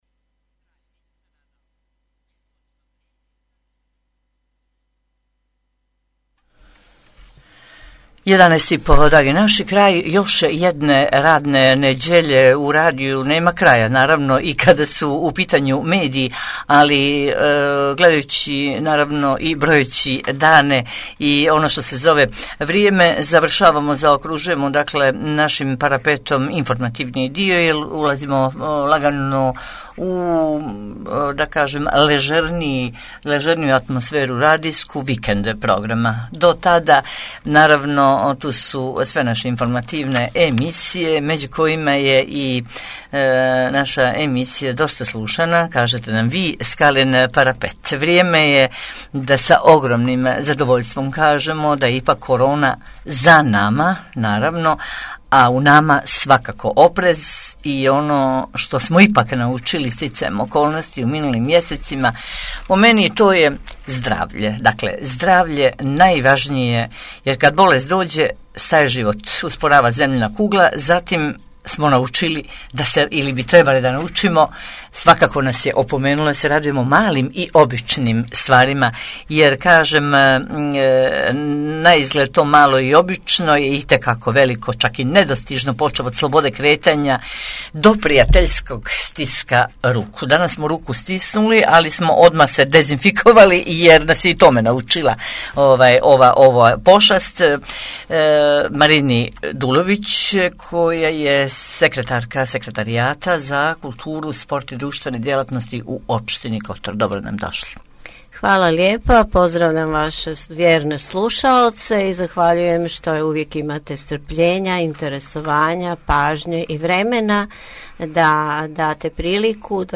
Gošća emisije Marina Dulović, sekretarka Sekretarijata za kulturu, sport i društvene djelatnosti opštine Kotor